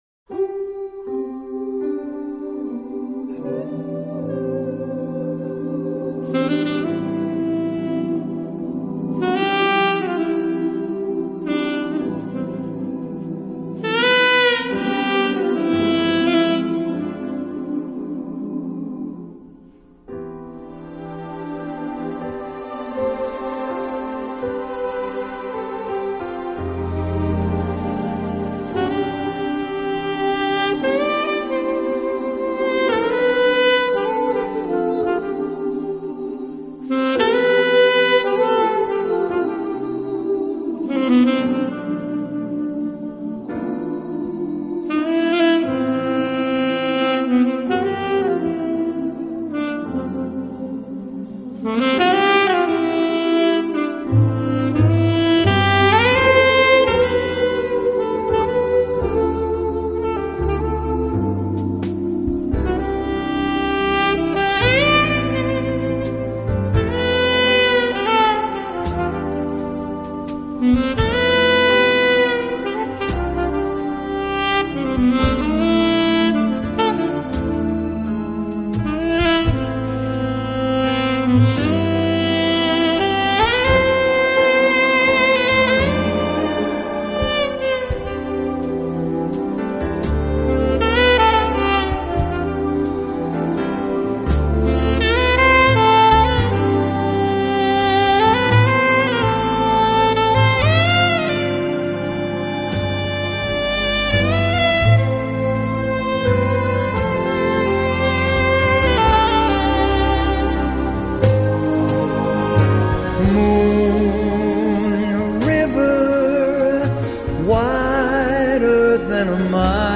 【爵士萨克斯】
Category : Rock/Pop, Rock, Jazz
Studio/Live : Studio